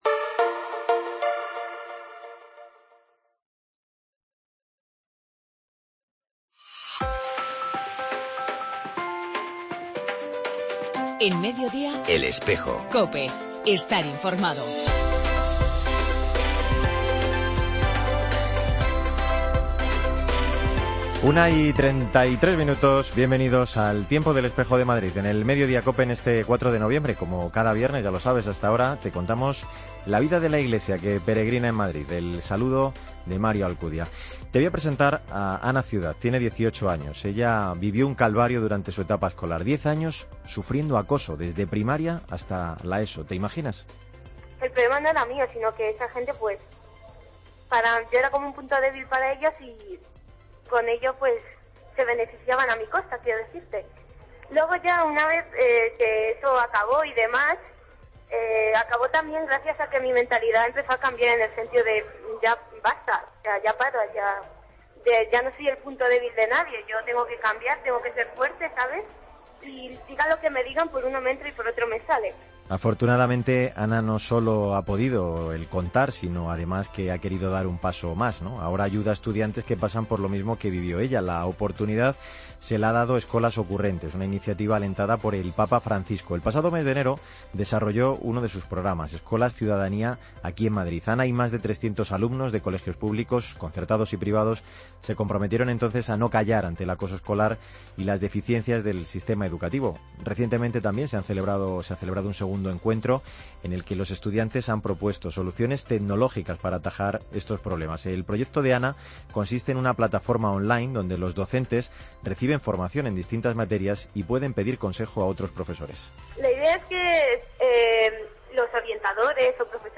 AUDIO: Encuentro Diocesano de Niños; entrevista al cardenal Rouco Varela con motivo de sus 40 años como obispo y actos con motivo de la fiesta...